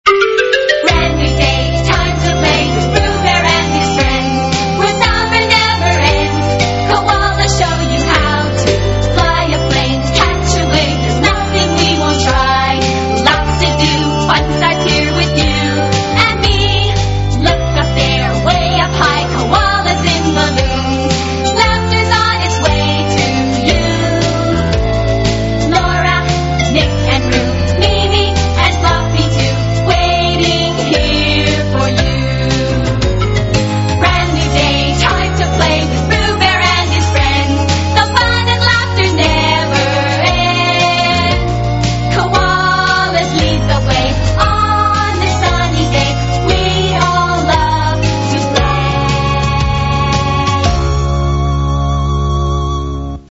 Theme Song